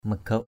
/mə-ɡ͡ɣəʊ˨˩ʔ/ (đ.) chúng bây, các người (thân mật) = vous autres. you guys!